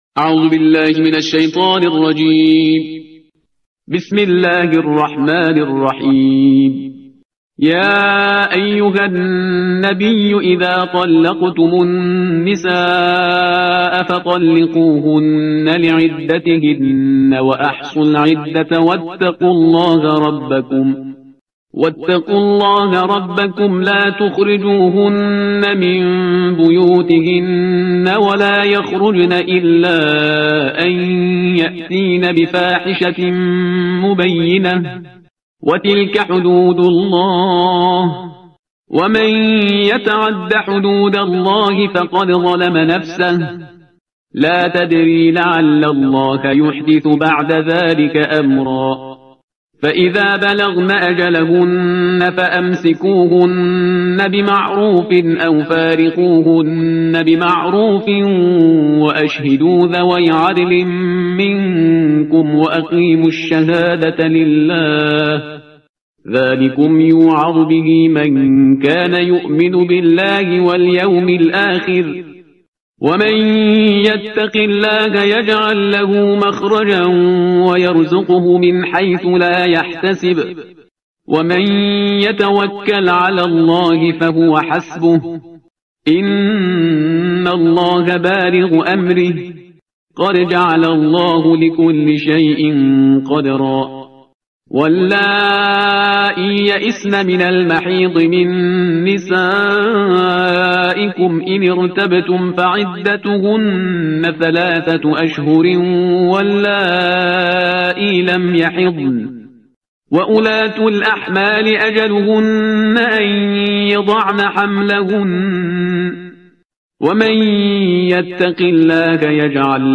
ترتیل صفحه 558 قرآن – جزء بیست و هشتم